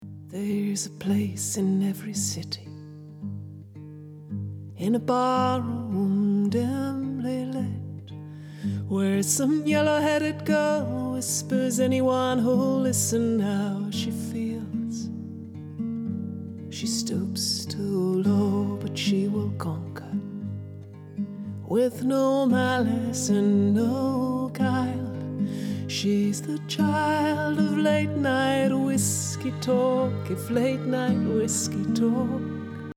dans un premier exemple il y a d'abord un compresseur genre opto avec un attack très longue (et release en auto) suivi d'un comp à VCA avec Attack moyen et release moyen :
je précise aussi qu'il fallait rebaisser le volume sonore pourque la chose reste comparable avec l'originale... notez aussi comment la reverbe gagne en présence et devient limite envahissante... et notez comment la compression joue sur le grain et la coloration du bas-mid !!